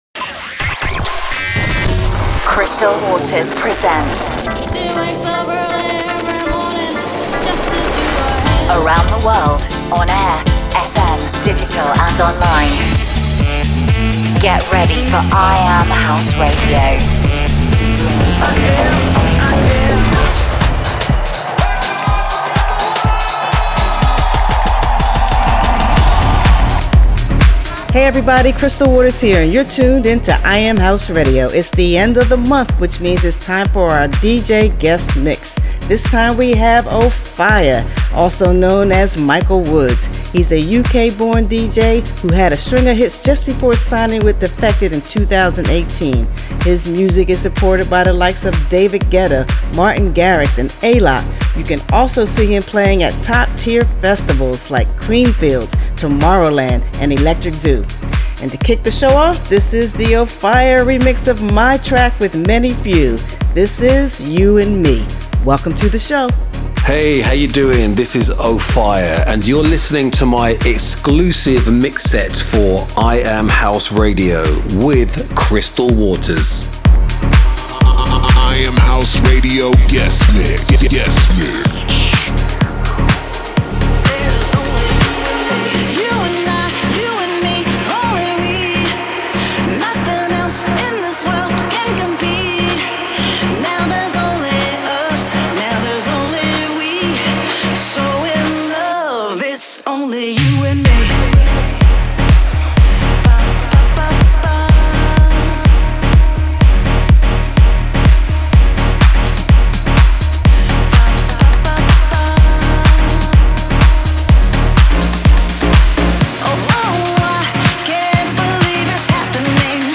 Playing the best new House Music from around the world.